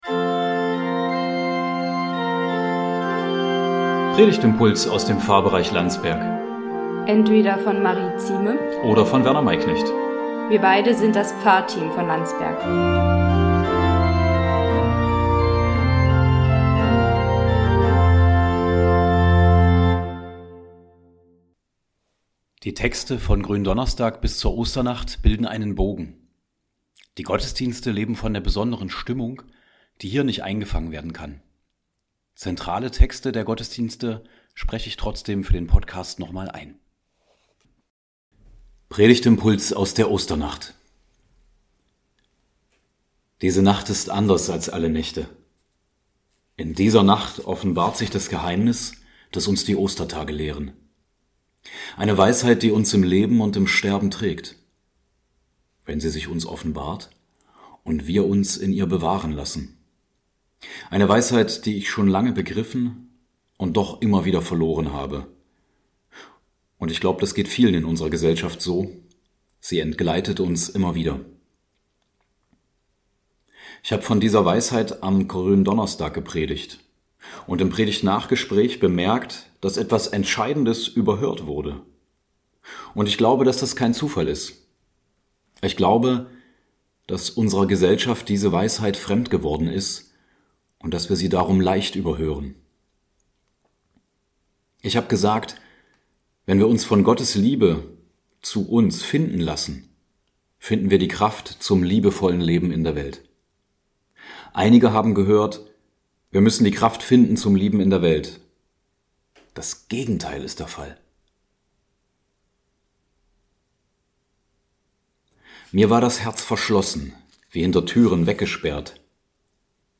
Predigtimpulse aus dem Pfarrbereich Landsberg